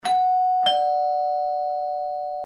doorbell